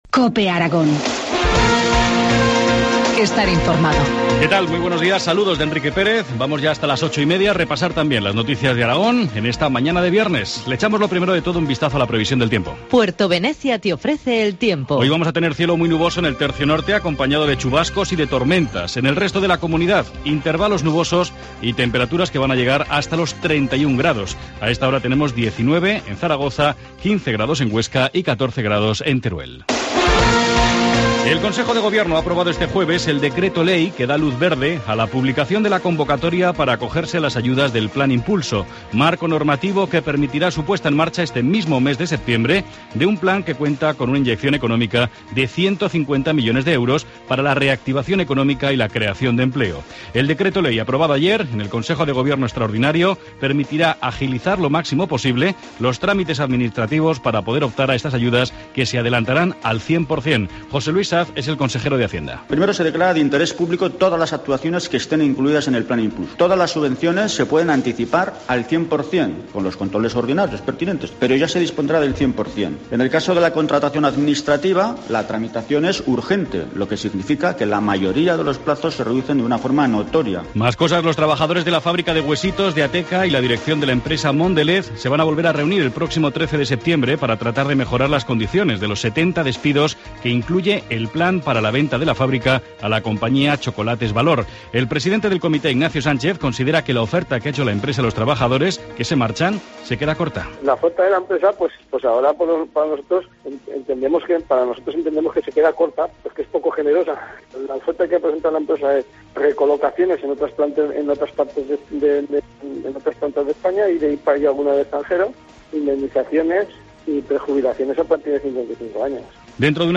Informativo matinal, viernes 6 de septiembre, 8.25 horas